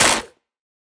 Minecraft Version Minecraft Version 1.21.5 Latest Release | Latest Snapshot 1.21.5 / assets / minecraft / sounds / block / mangrove_roots / break4.ogg Compare With Compare With Latest Release | Latest Snapshot
break4.ogg